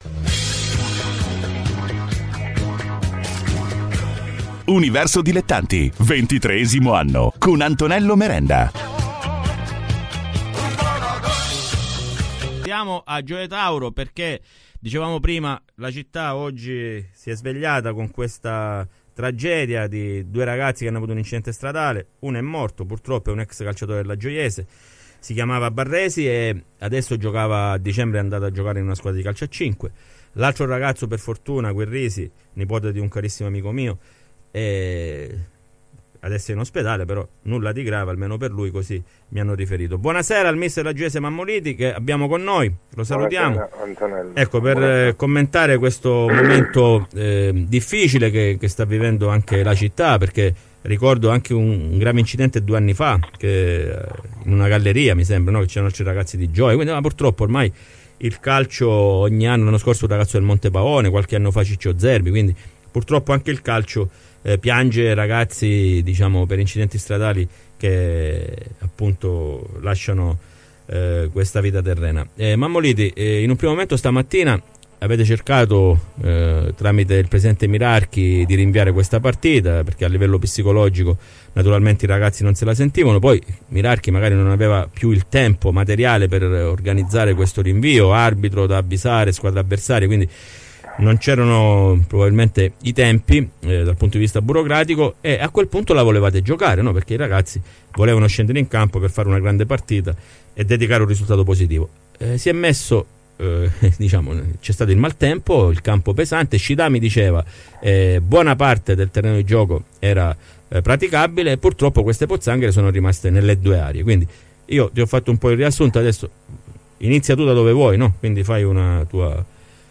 Le interviste ai protagonisti di Universo Dilettanti